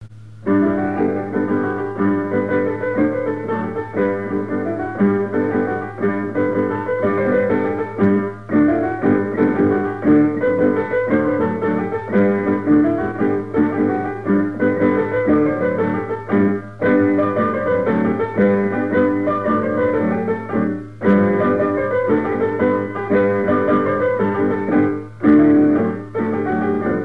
danse : branle : avant-deux
Trois avant-deux au piano, et un quadrille aux cuivres
Pièce musicale inédite